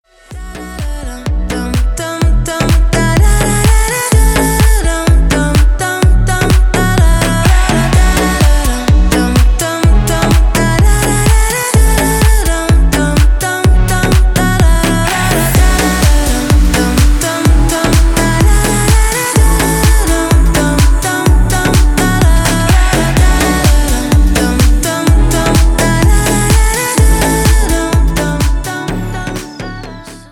• Песня: Рингтон, нарезка
играет Dance рингтоны🎙